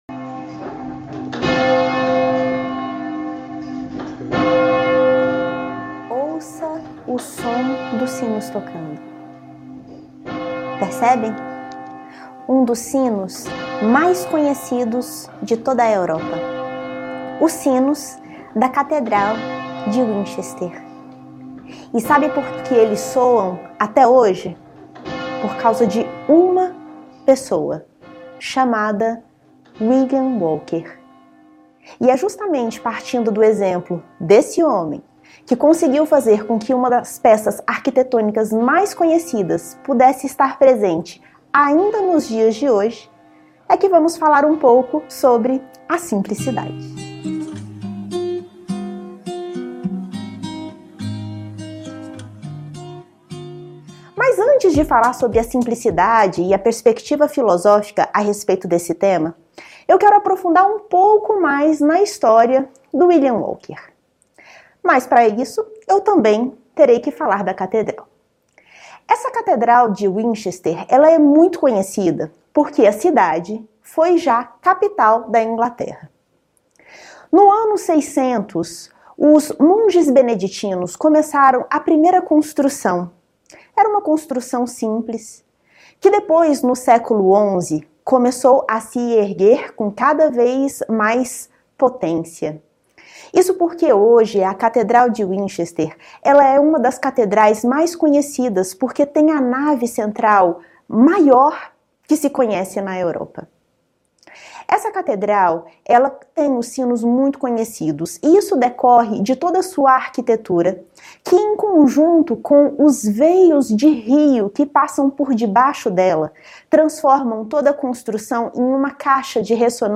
Palestras Filosóficas Nova Acrópole